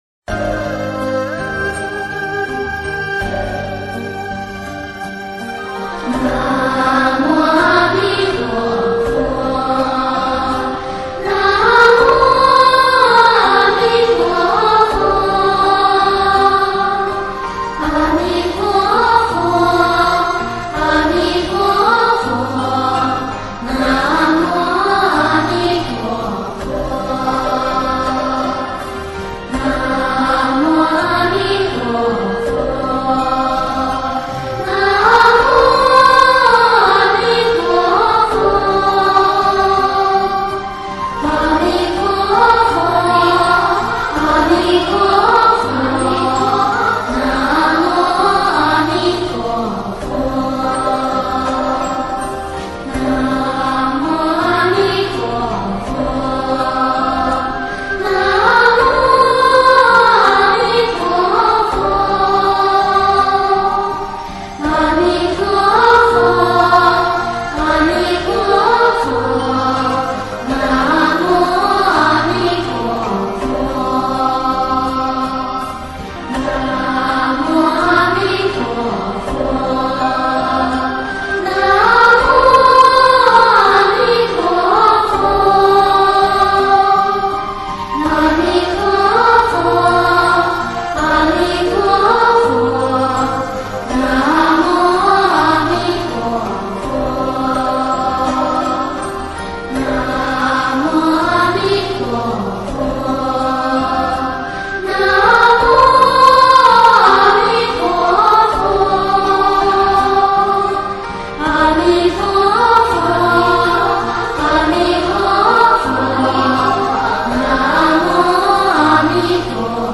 童音清净柔和，适合家庭共修、静心听闻与日常佛号熏修。
阿弥陀佛圣号（童音）
17-阿弥陀佛（童音）.mp3